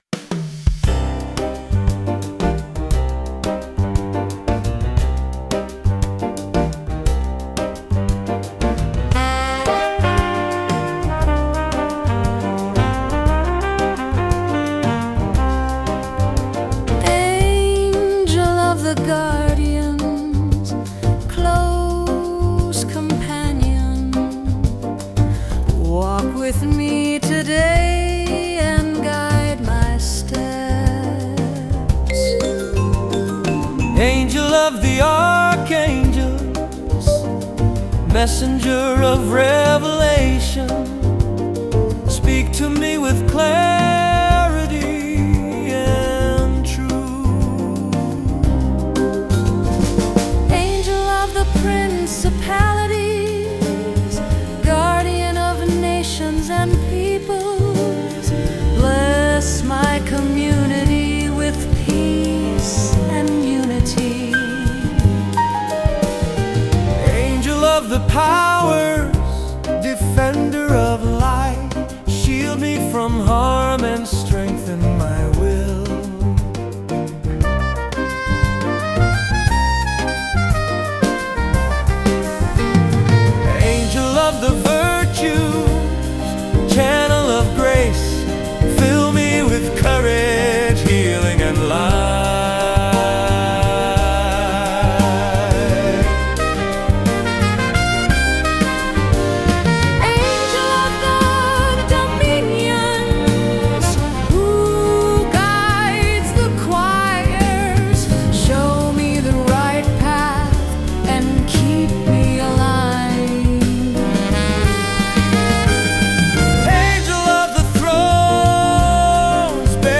progressive jazz ensemble